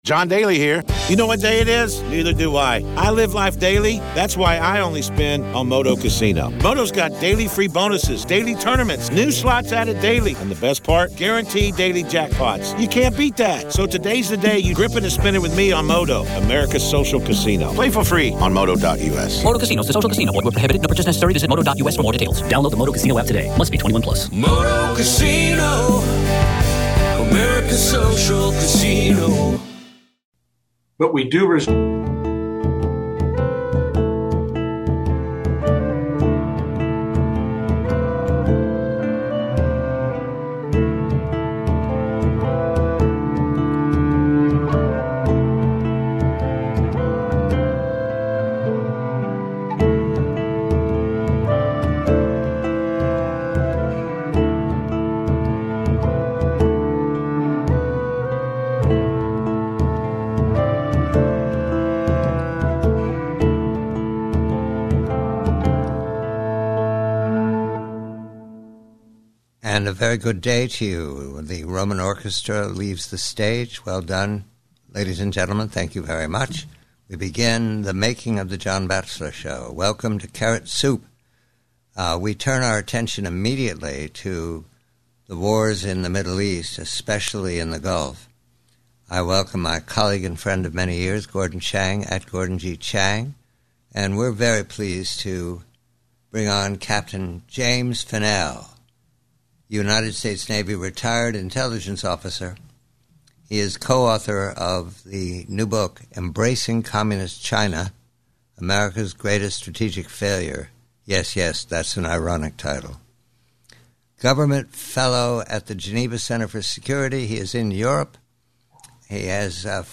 This transcript from a broadcast of The John Bachelor Show features a roundtable discussion with experts regarding China’s involvement in Middle Eastern conflicts and its broader strategic goals. The participants examine a report alleging that Iran utilized Chinese satellite data to coordinate strikes against American military positions, leading to a debate over whether President Trump should proceed with a planned visit to Beijing.